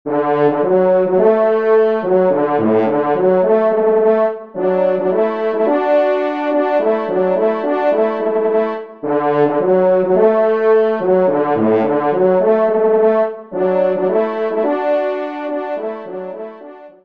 Genre :  Divertissement pour Trompes ou Cors
2e Trompe